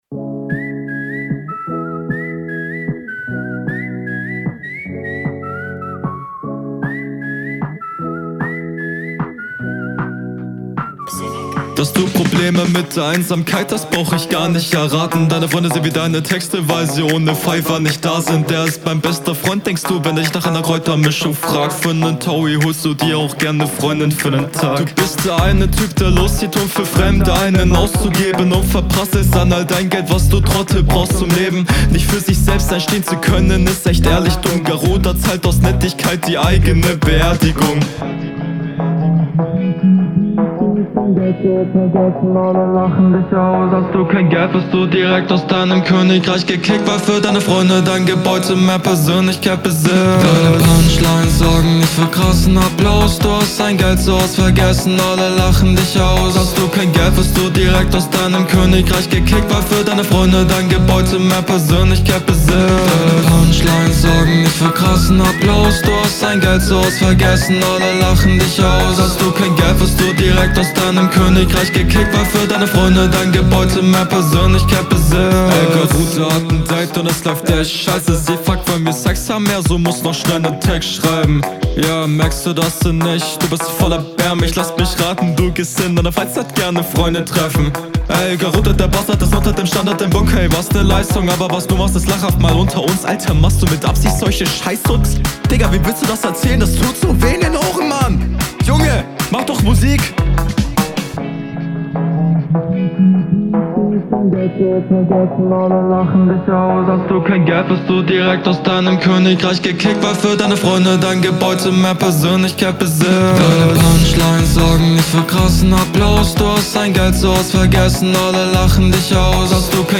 Flow ganz okay, Hook verbessert meinen Eindruck da doch deutlich.